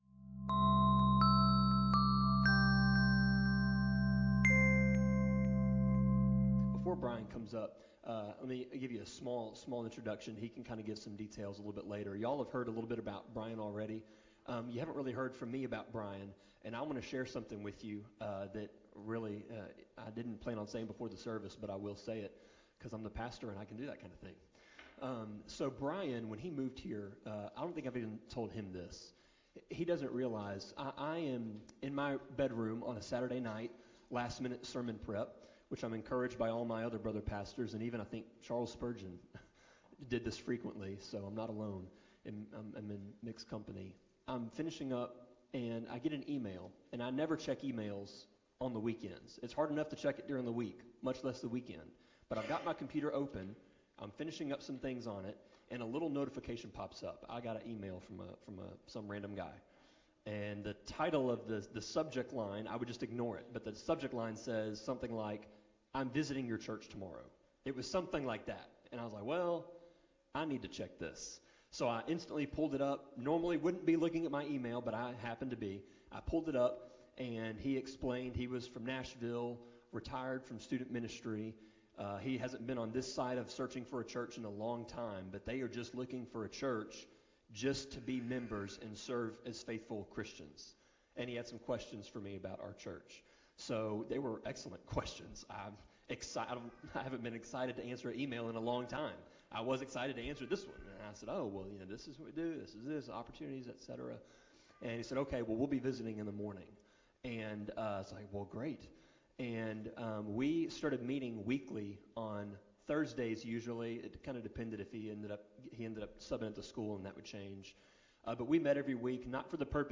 Sermon-25.12.7-CD.mp3